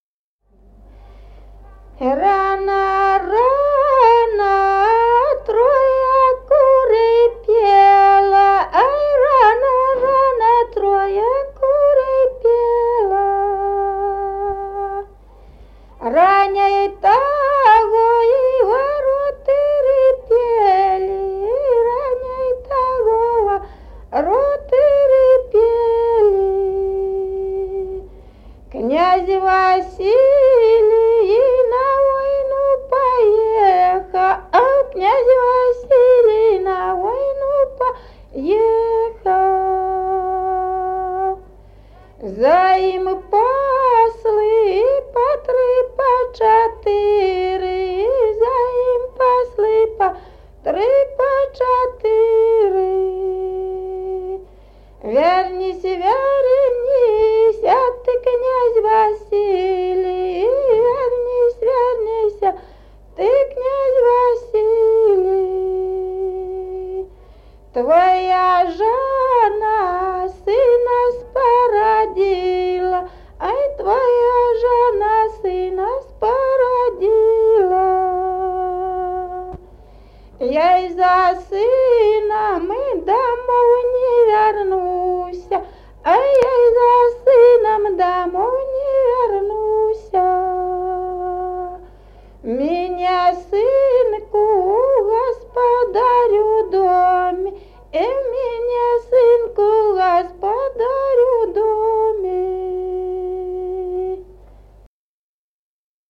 Народные песни Стародубского района «Рано, рано трое куры», крестинная.
с. Мохоновка.